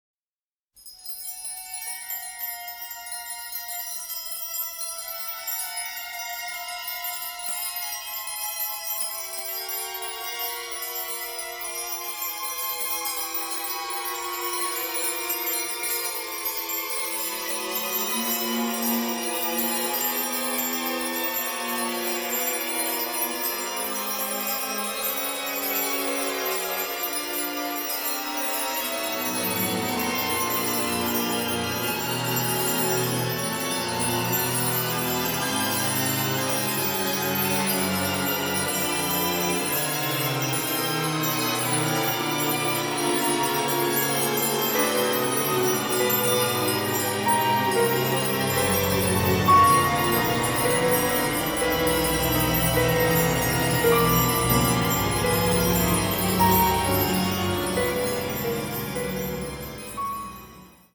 giallo score
sophisticated avant-garde sound